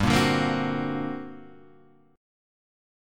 G Minor Major 11th